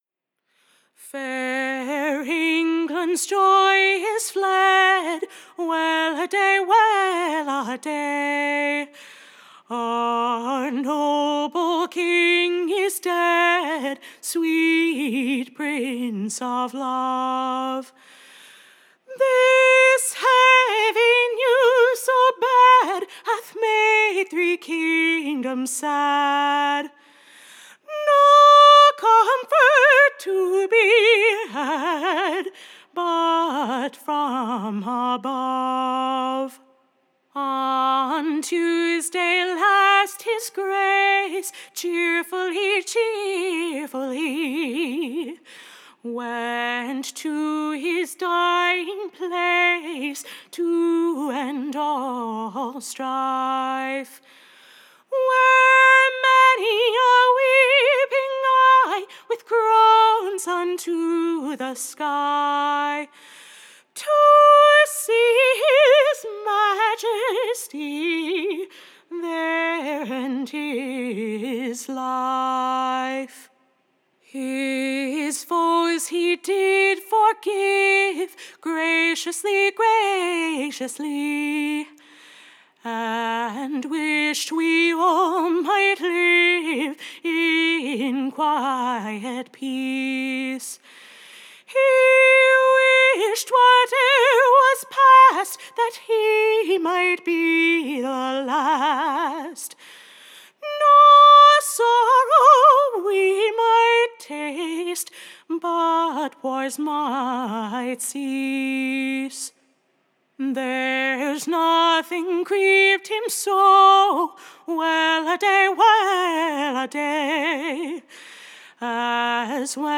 Recording Information Ballad Title King CHARLES / His Speech, and last Farewell to the World.
Tune Imprint To the Tune of, Weladay.